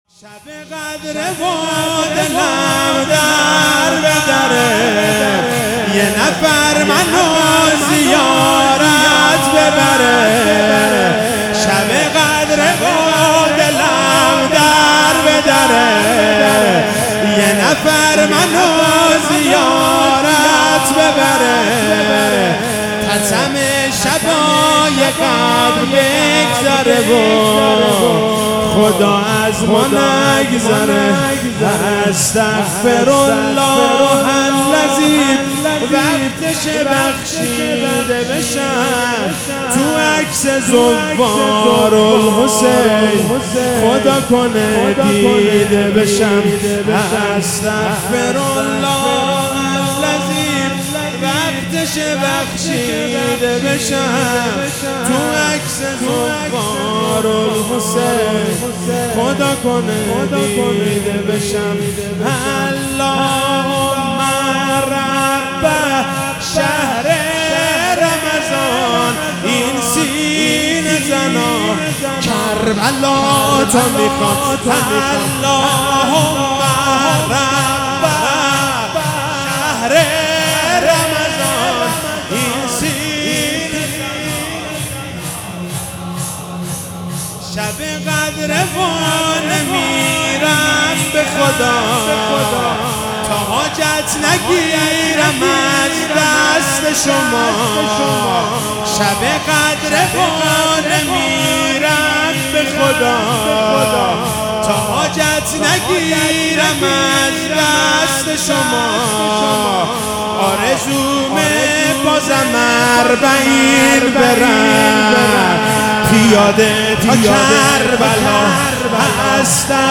شب 21 رمضان 97 - شور